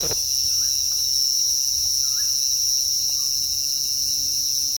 Short-tailed Nighthawk (Lurocalis semitorquatus)
Location or protected area: Parque Nacional Iguazú
Condition: Wild
Certainty: Photographed, Recorded vocal
Anapero-castano.mp3